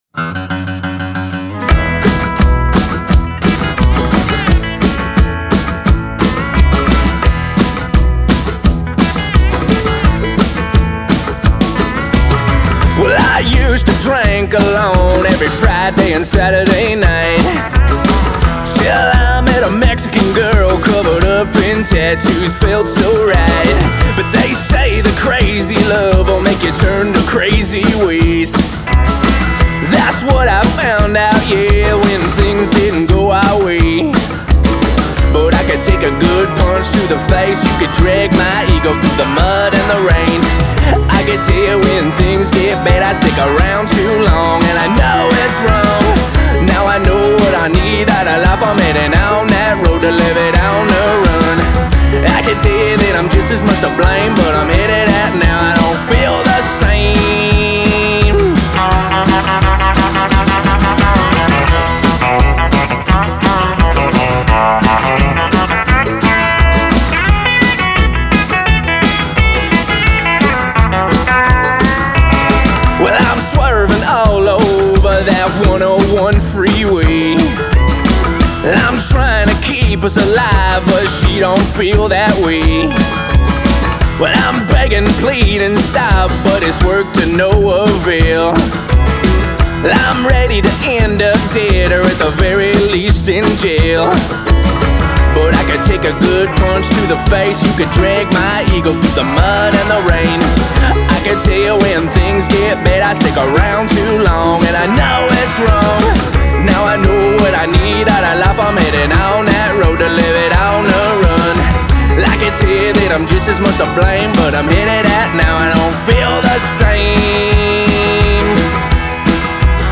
They’re Country, they’re Punk and they’re Rock 'N Roll.